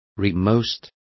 Complete with pronunciation of the translation of rearmost.